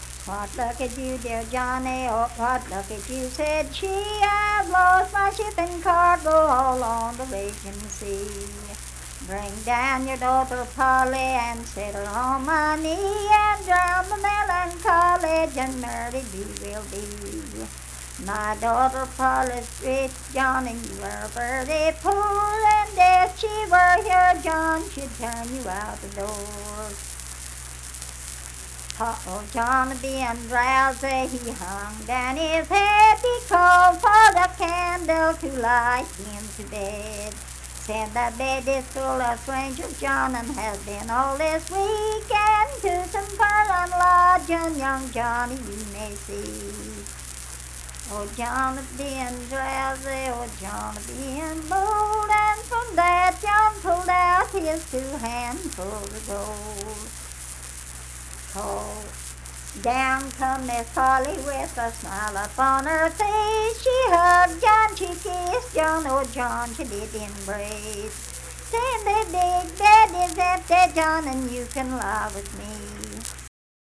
Unaccompanied vocal music performance
Verse-refrain 8(4).
Voice (sung)